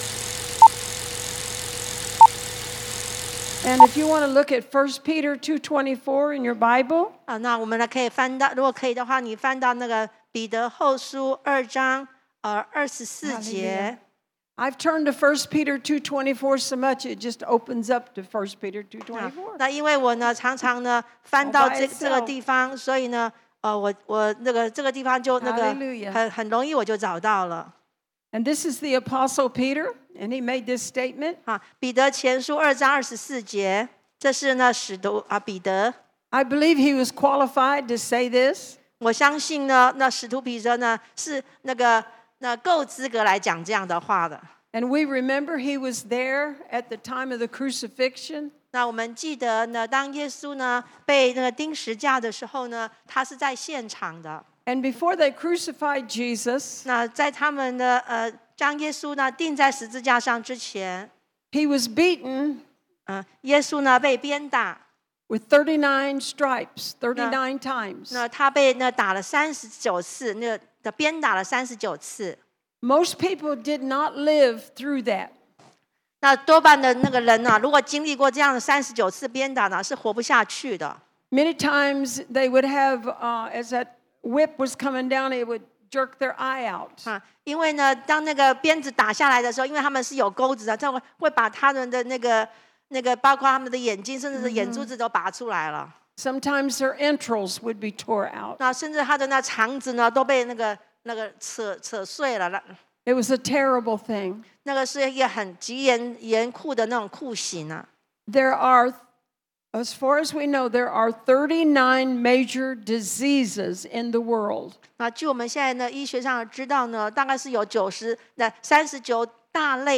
地點：台南CPE領袖學院